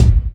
Wu-RZA-Kick 37.wav